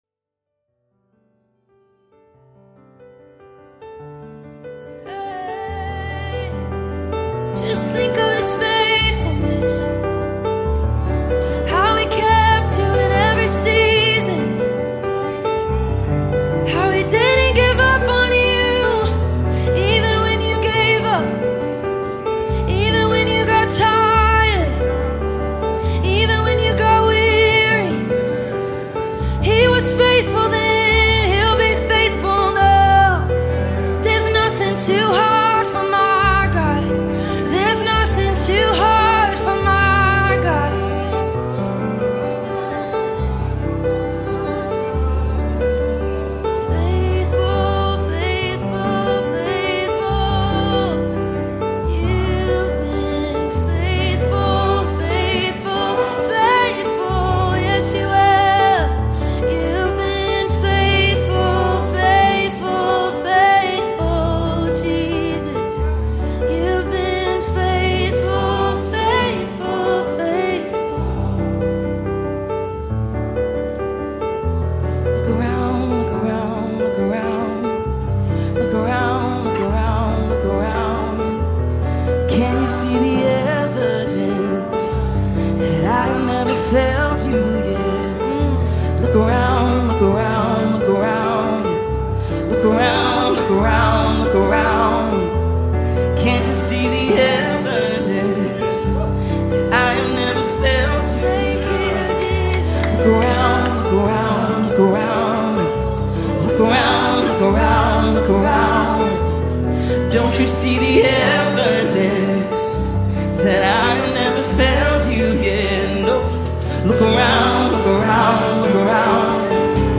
deliver breathtaking vocal performances
unique live recordings